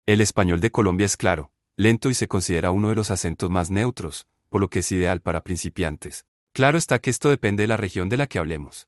• Colombian Spanish is clear, slow, and considered one of the most neutral accents, making it ideal for beginners.
acento-de-Colombia.mp3